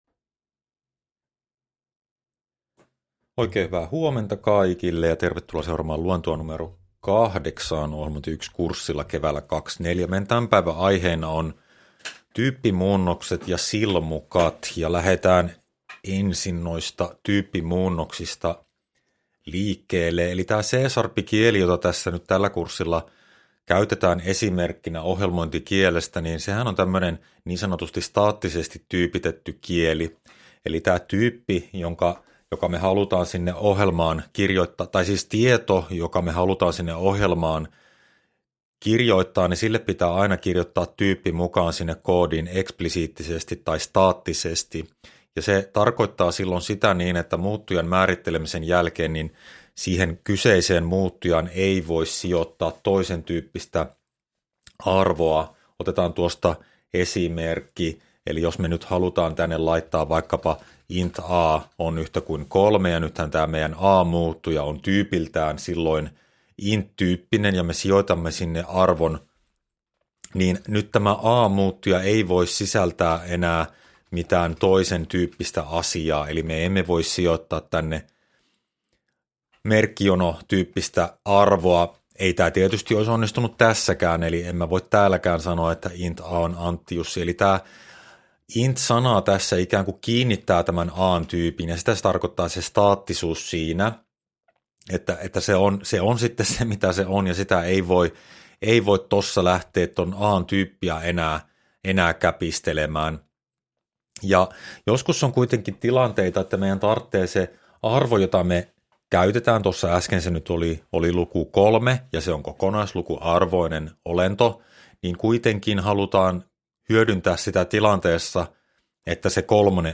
Luento 8 — Moniviestin